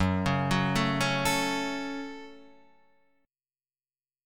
F#m chord